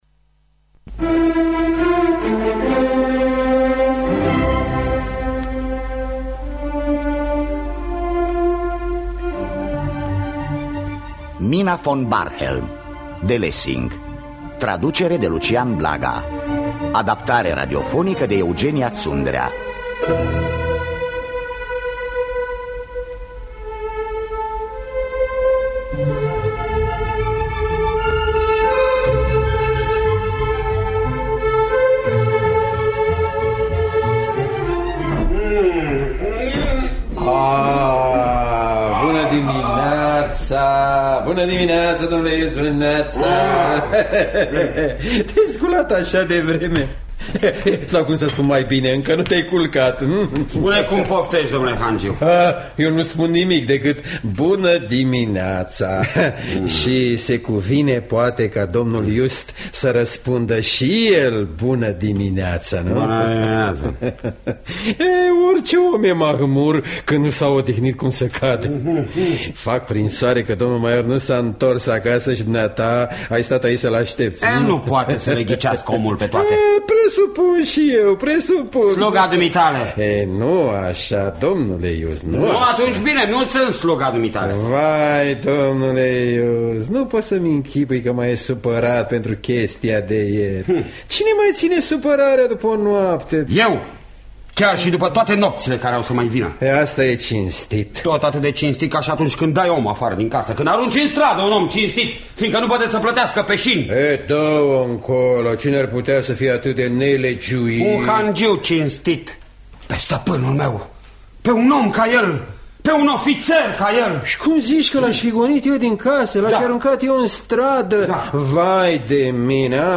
Adaptare radiofonică